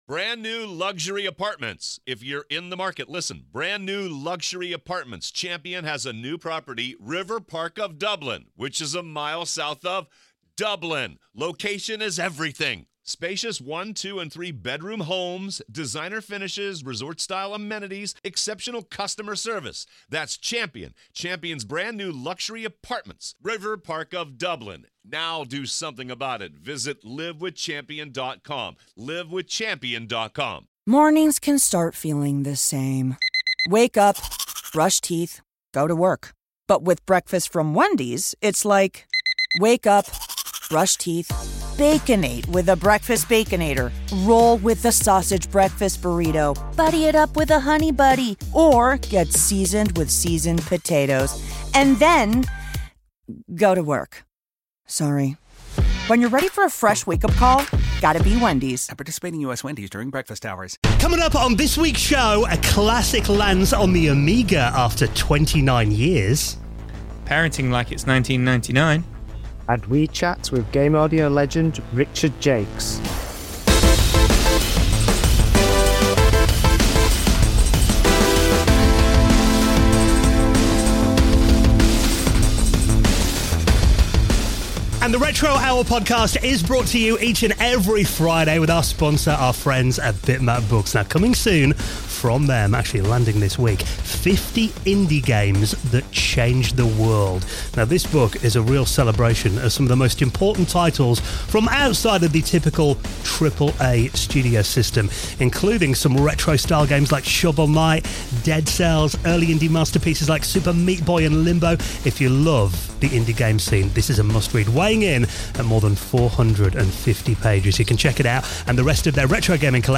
This week’s episode comes live from Retromessa 2025 in Norway, where we were joined on stage by legendary video game composer Richard Jacques.
54:42 - Richard Jacques Interview